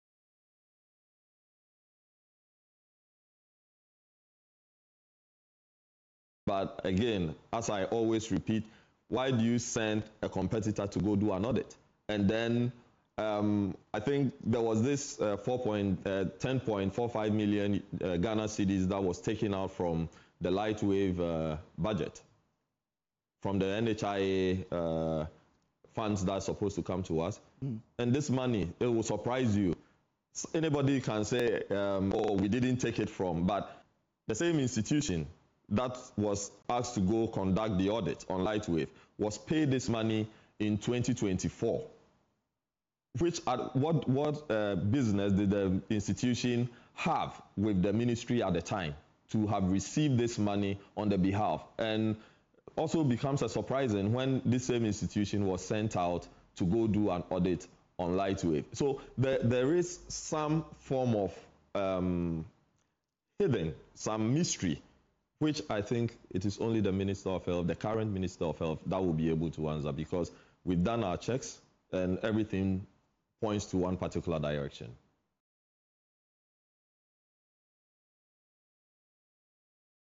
Speaking on the JoyNews AM Show, he suggested that some issues may be linked to personal or institutional conflicts rather than genuine procedural lapses.